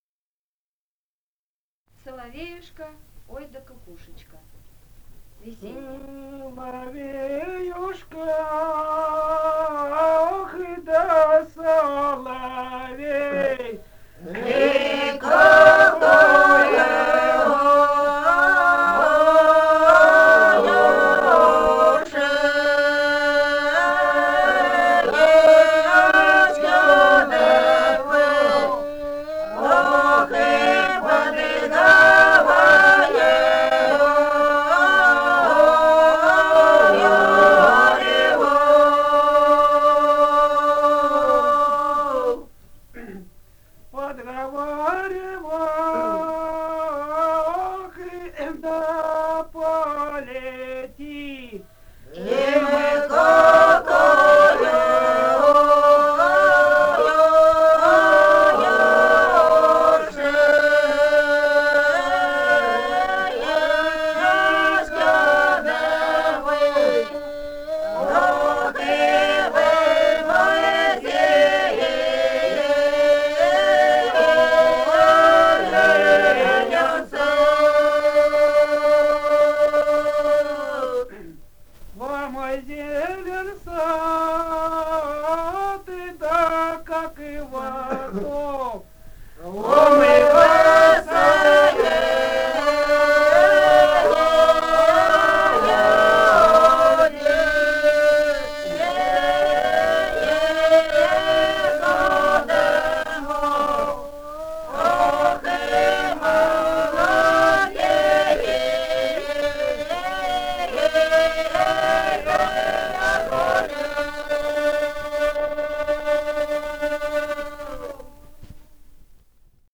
Этномузыкологические исследования и полевые материалы
Алтайский край, с. Михайловка Усть-Калманского района, 1967 г. И1001-18